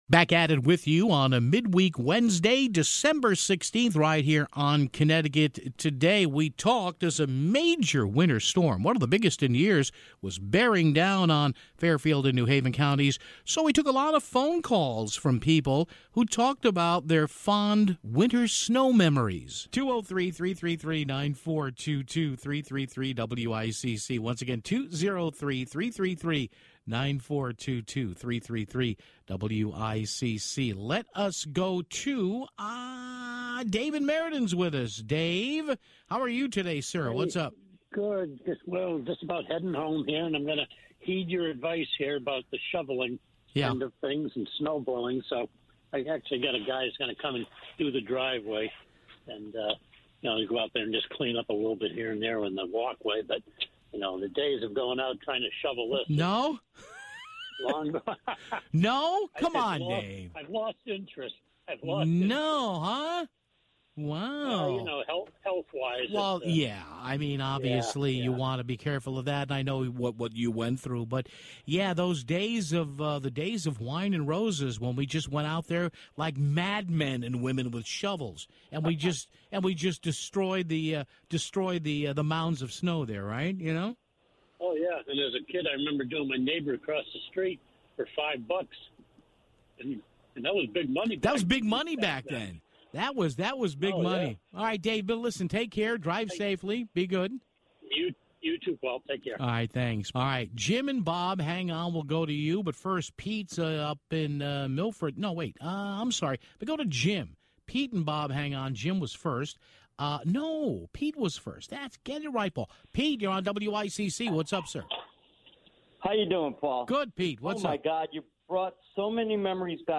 took a lot of calls from listeners on snow memories, stories and just overall silliness